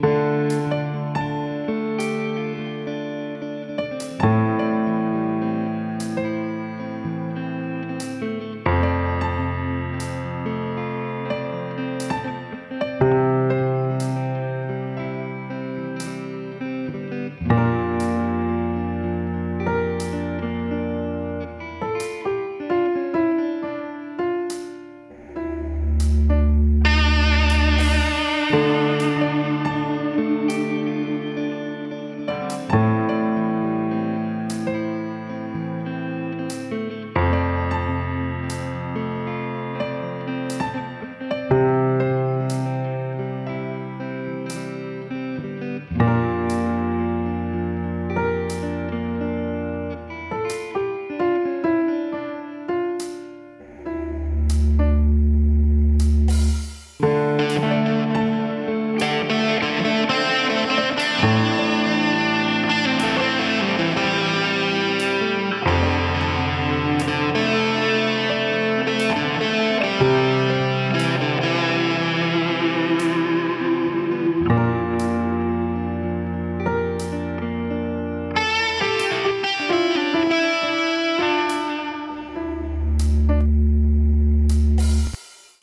Plongez dans une Ambiance Chill Lofi